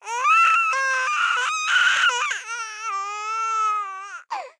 sob.ogg